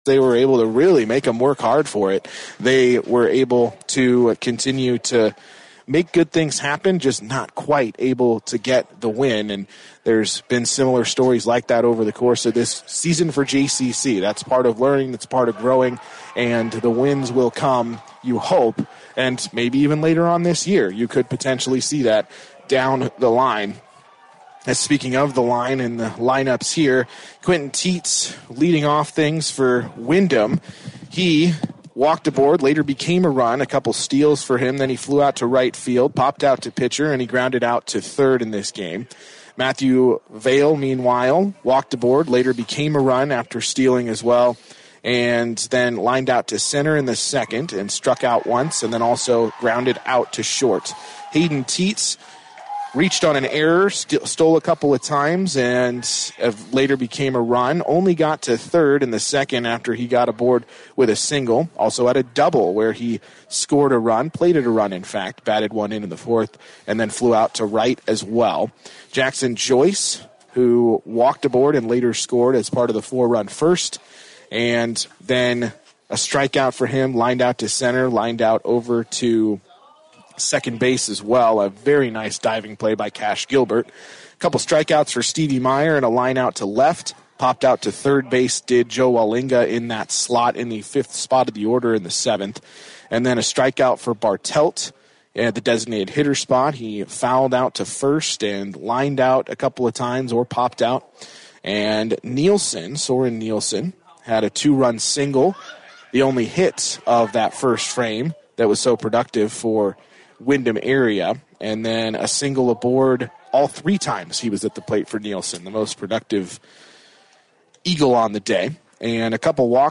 FULL POST-GAME SHOW Windom scored four runs in the first and added one more in the top of the fourth.